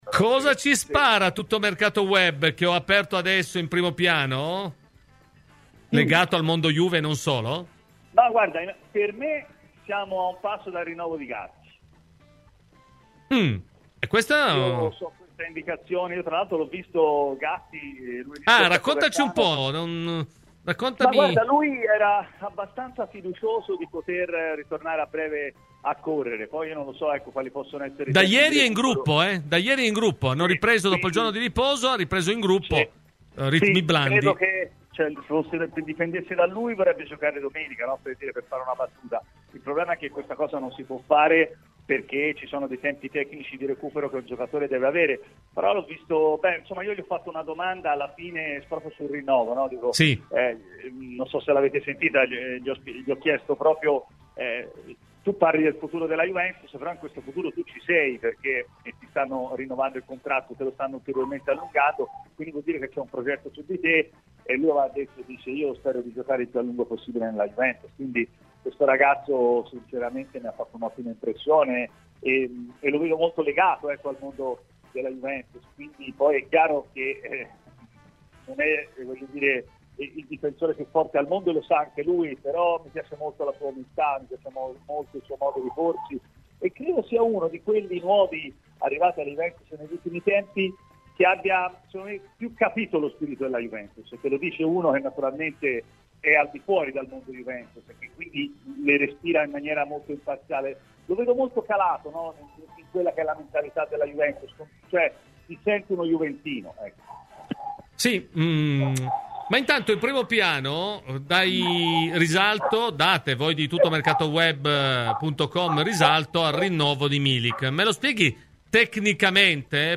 Ascolta l'intervento completo nel podcas allegato.